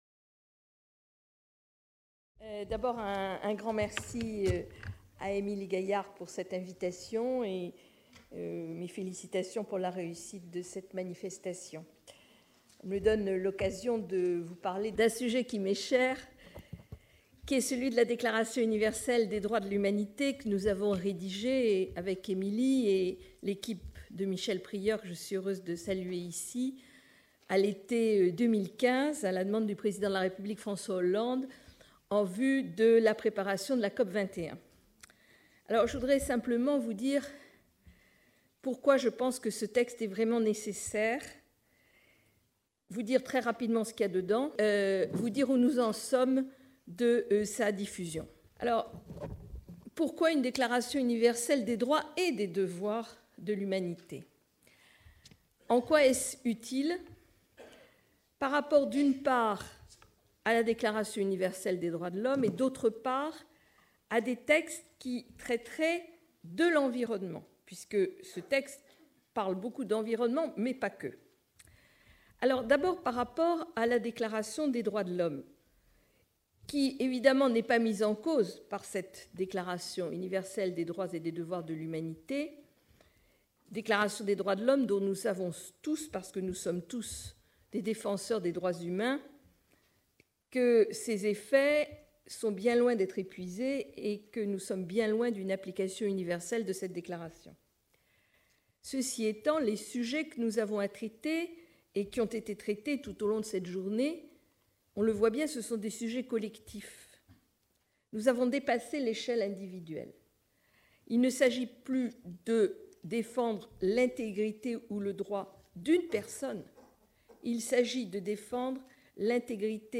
Cette communication a été prononcée dans le cadre du colloque international Agir en justice au nom des générations futures qui s'est tenu à Caen les 17 et 18 novembre 2017. Le concept même de Justice se comprend désormais en considération de la protection juridique des générations futures : justice environnementale, justice climatique (inscrite dans l’Accord de Paris), protections des biens communs sont autant de nouvelles facettes du concept de justice, spécifiques à notre temps.
Corinne Lepage est une avocate et une femme politique française engagée dans la protection de l'environnement.